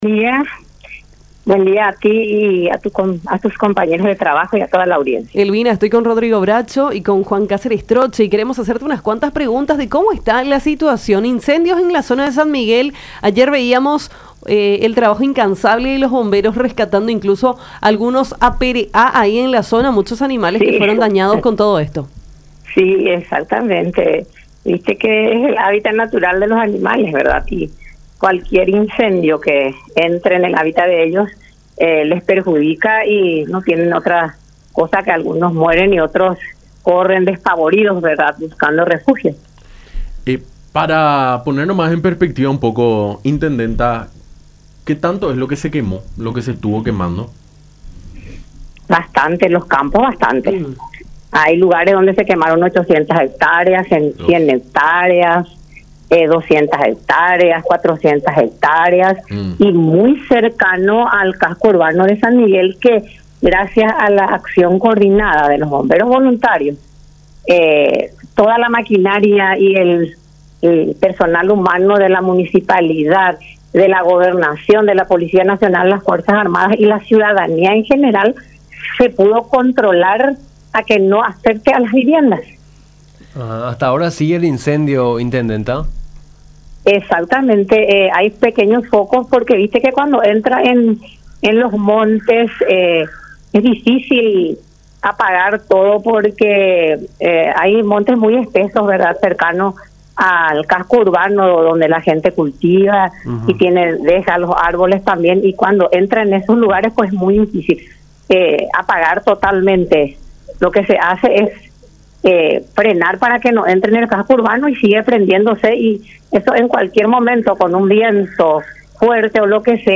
A su vez, Elvina González, intendenta de San Miguel, Departamento de Misiones, afirmó que la situación fue dramática porque en su localidad incluso los animales y las mismas viviendas estuvieron en peligro por las llamas.